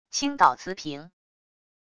倾倒瓷瓶wav音频